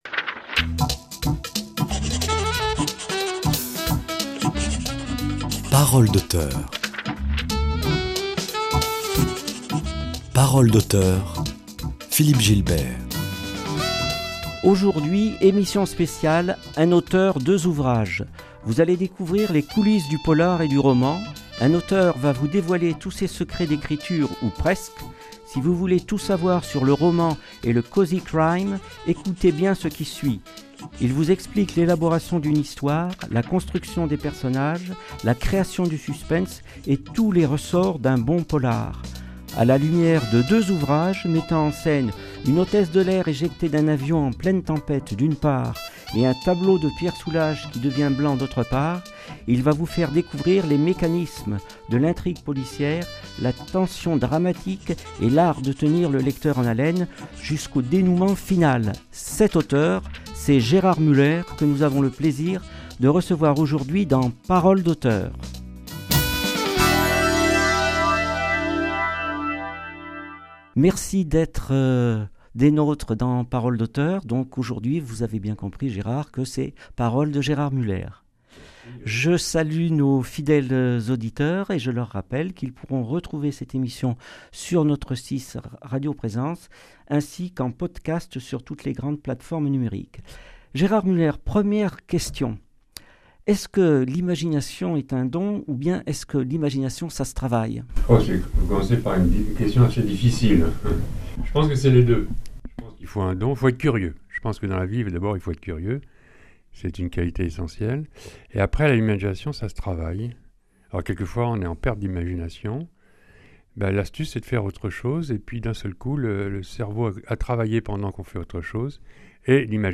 Aujourd'hui Émission spéciale un auteur deux ouvrages.
Un auteur va vous dévoiler tous ses secrets d'écriture ou presque.